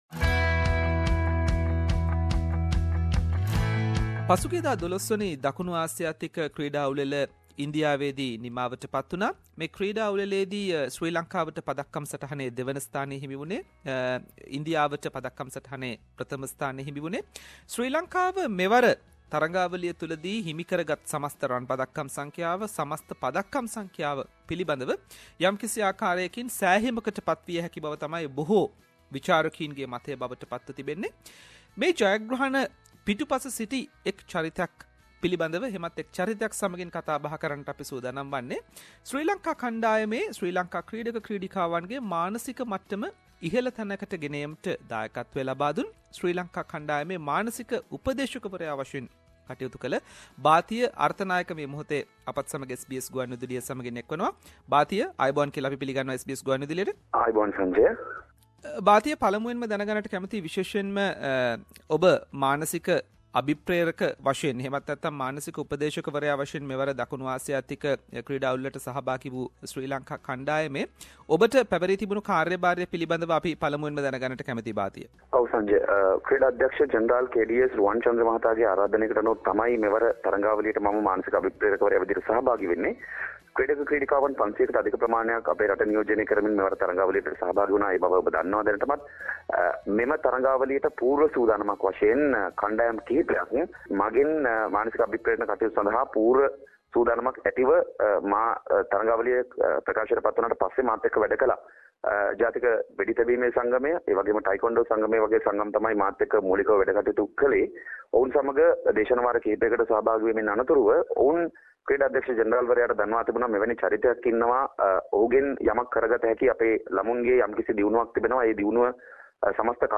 SBS Sinhalese interviewed him regarding his role.